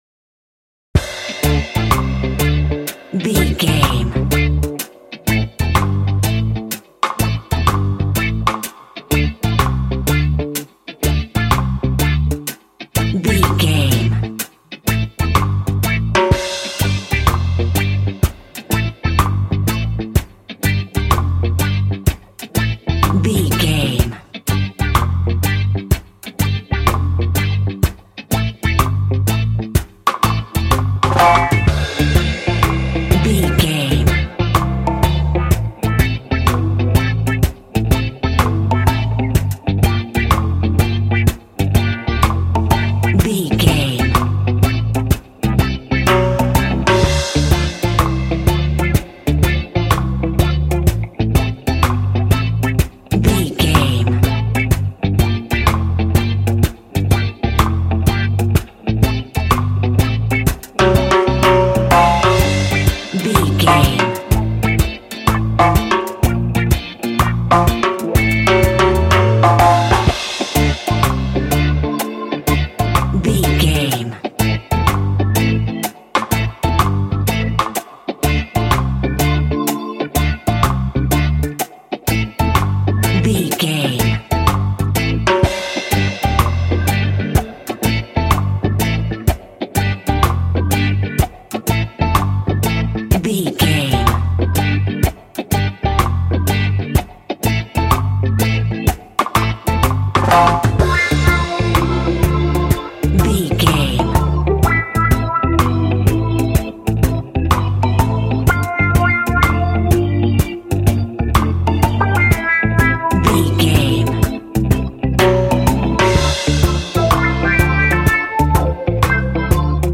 Aeolian/Minor
cheerful/happy
mellow
drums
electric guitar
percussion
horns
electric organ